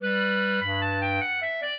clarinet
minuet2-4.wav